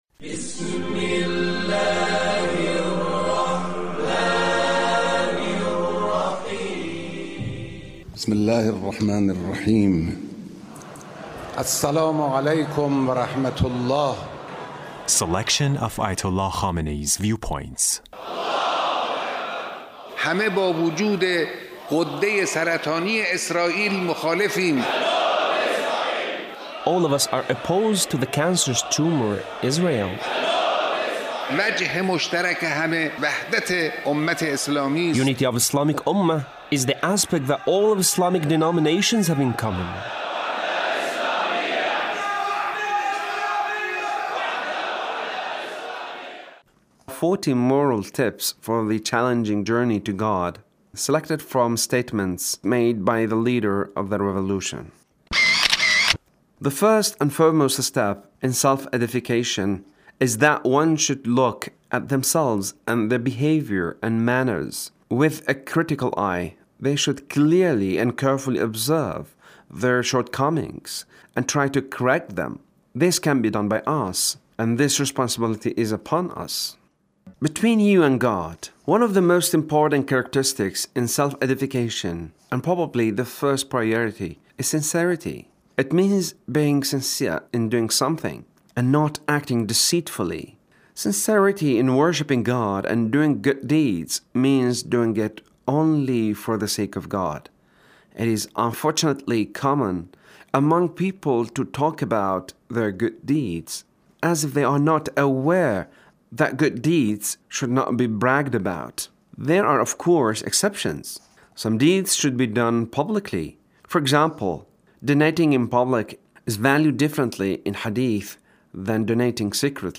Leader's speech (1356)